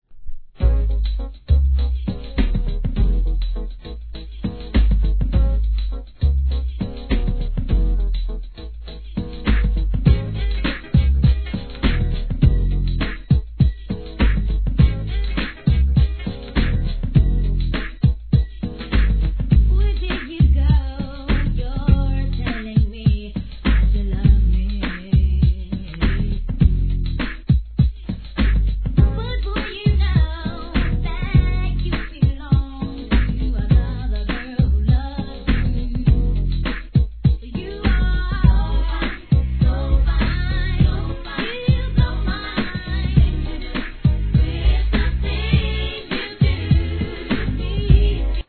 HIP HOP/R&B
NEW JACK SWING調の跳ねたBEATの中にも重量感を残した'92人気シングル!!